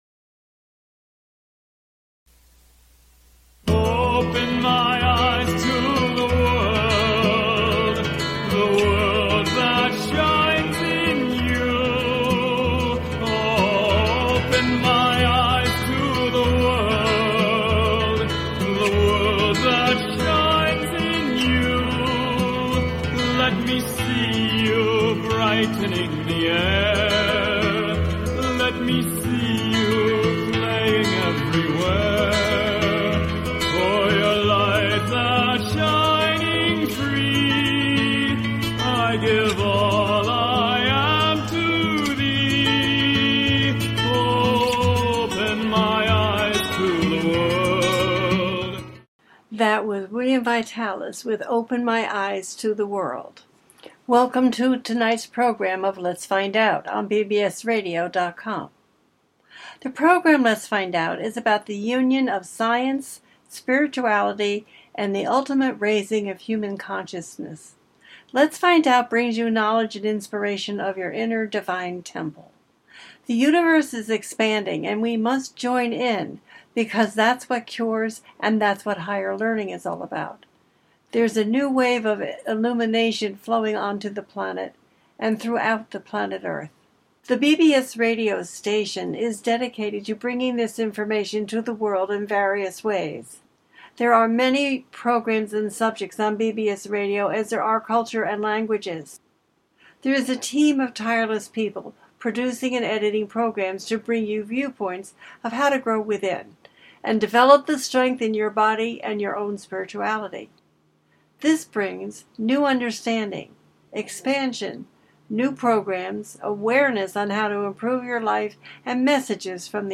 The listener can call in to ask a question on the air.
Each show ends with a guided meditation.